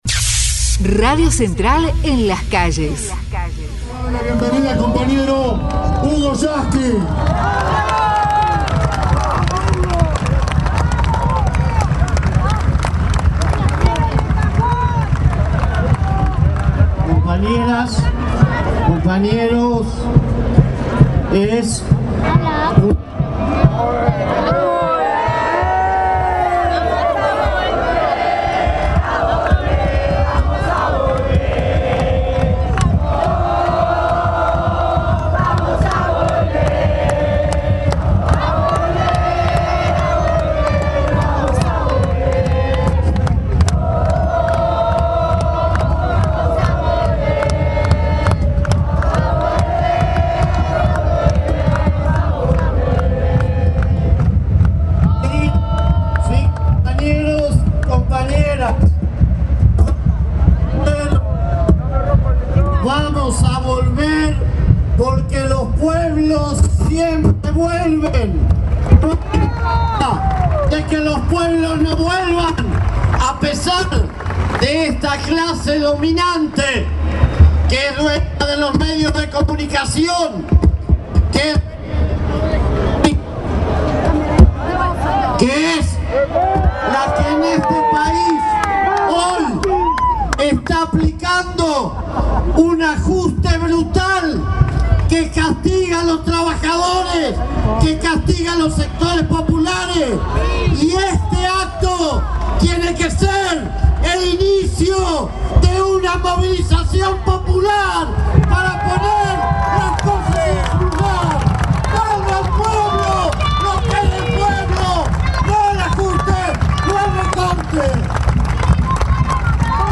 HUGO YASKY - MARCHA: LA LEY DE MEDIOS NO SE TOCA
Secretario General de la Central de Trabajadores de la Argentina
hugo_yasky_congreso_2015.mp3